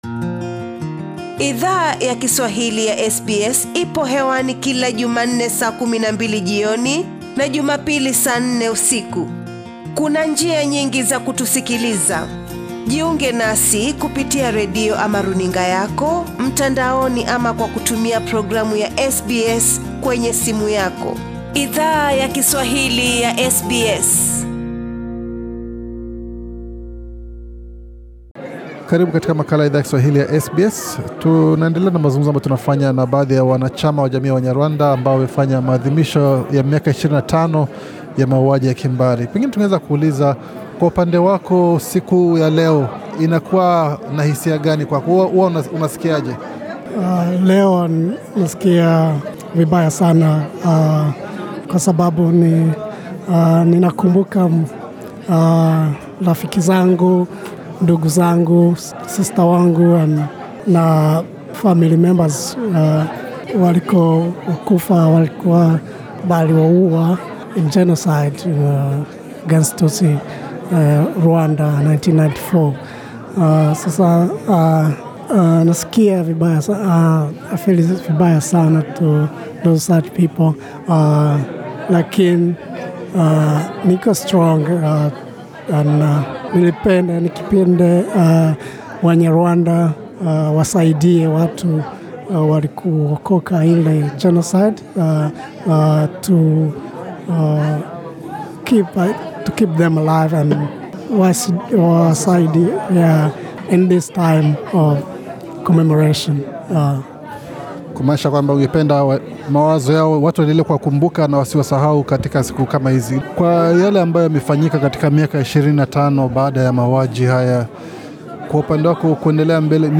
SBS Swahili ilihudhuria ibada iliyo andaliwa na jamii yawanyarwanda wanao ishi NSW, Australia ambako walifanya maadhimisho ya miaka 25 ya mauaji hayo ya kimbari. Bofya hapo juu kusikiza mazungumzo tuliyo fanya na baadhi ya wahanga wa mauaji hayo pamoja na viongozi wa jamii hiyo.